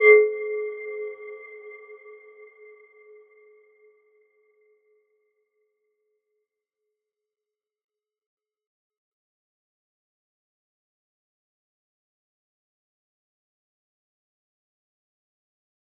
Aurora-B4-f.wav